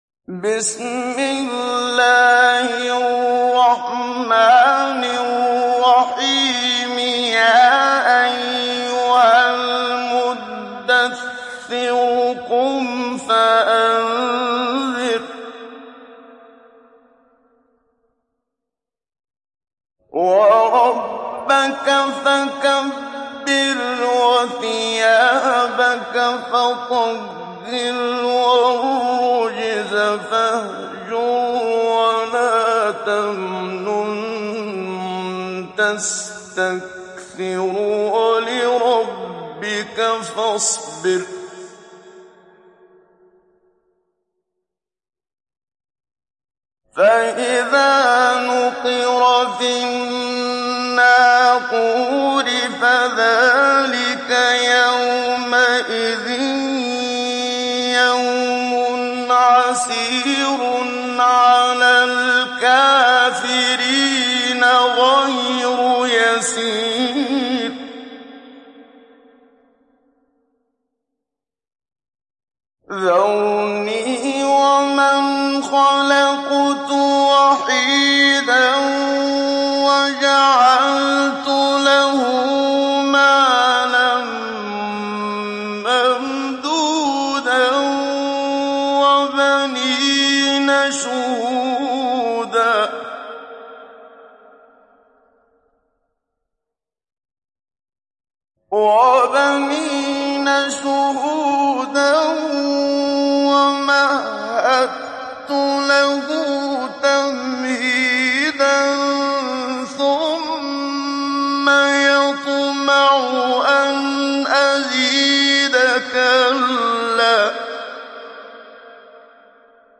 İndir Müdahhir Suresi Muhammad Siddiq Minshawi Mujawwad
Mujawwad